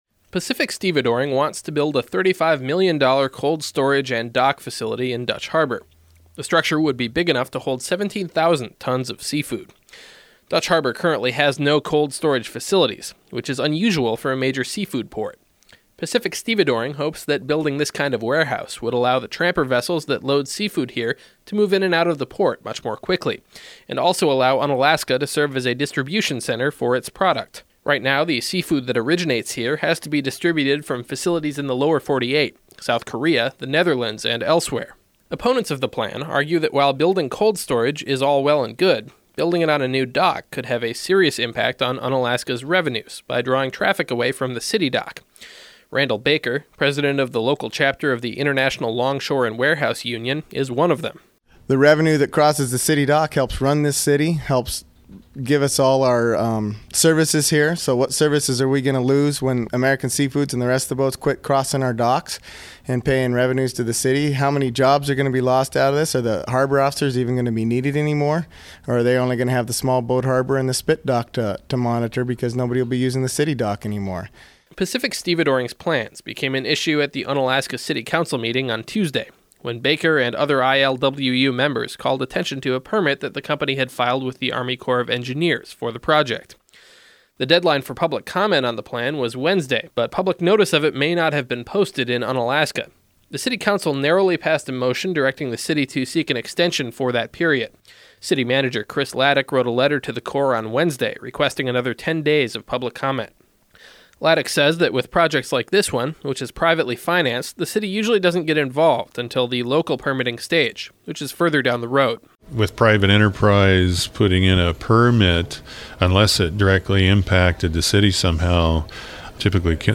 By KIAL News